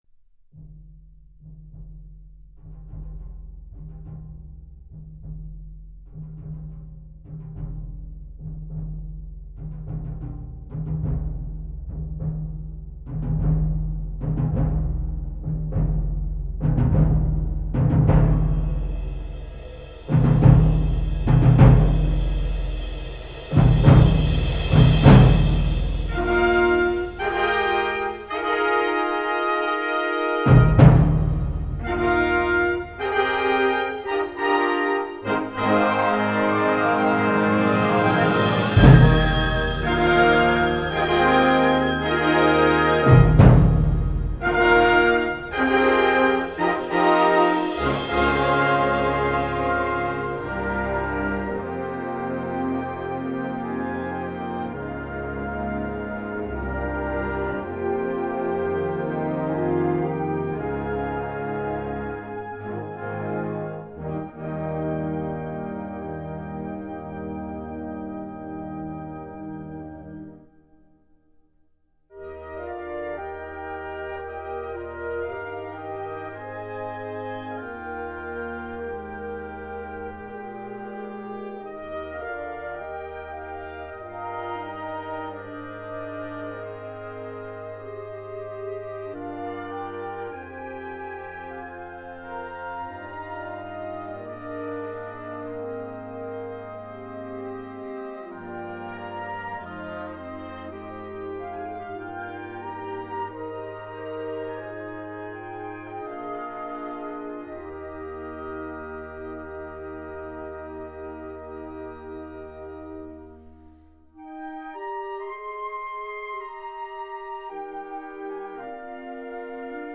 23 x 30,5 cm Besetzung: Blasorchester Tonprobe